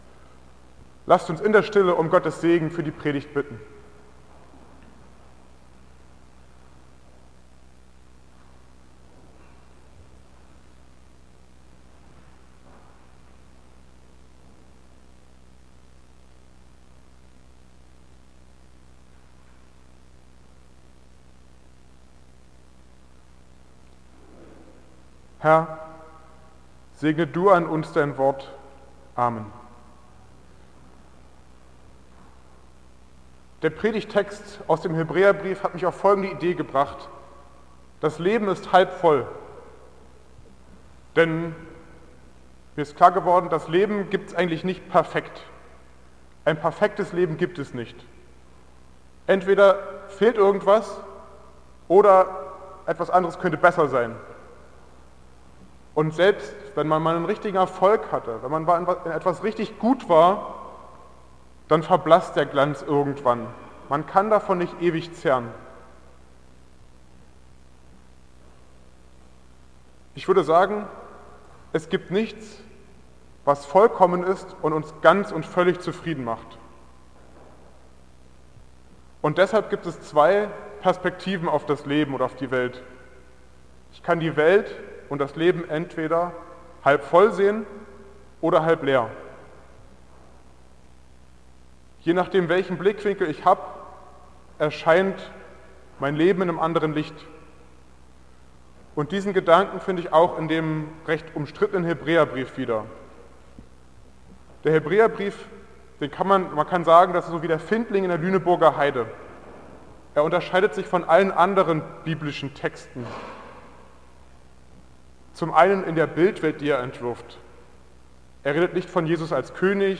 St.-Andreas-Kirchgemeinde Chemnitz-Gablenz | News-Tag | Orgelkonzert